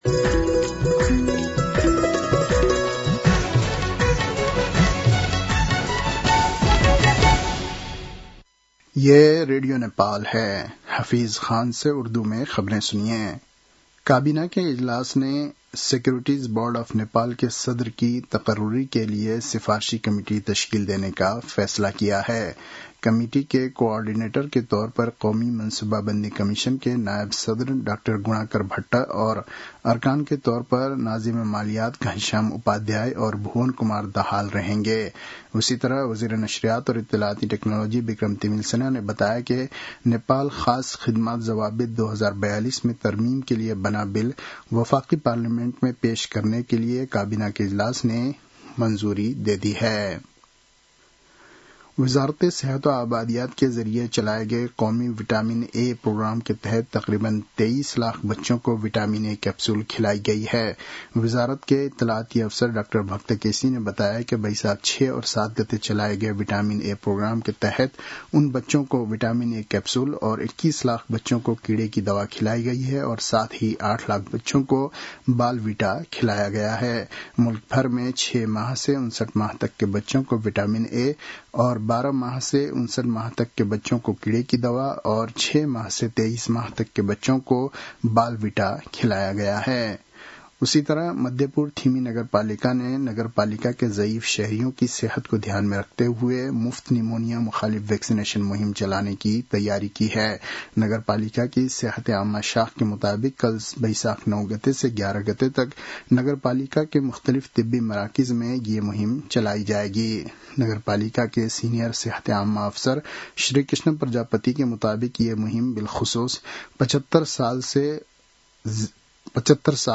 उर्दु भाषामा समाचार : ८ वैशाख , २०८३